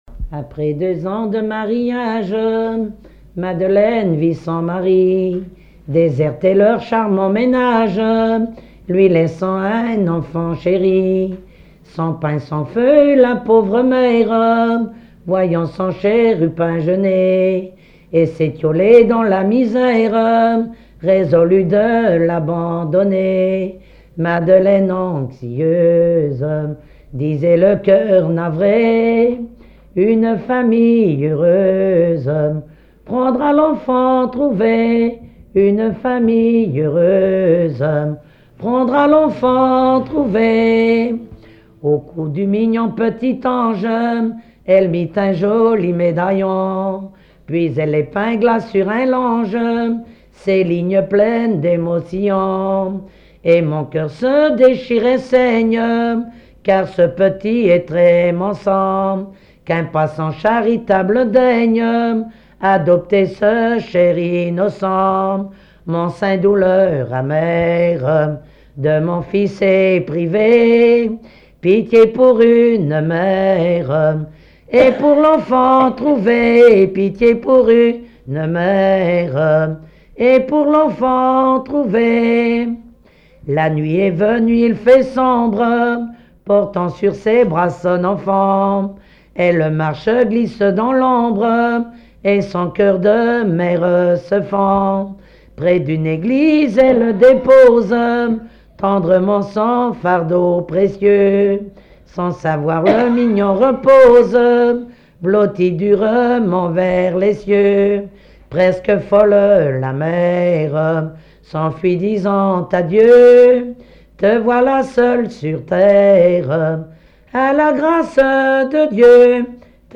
Genre strophique
collecte en Vendée
Pièce musicale inédite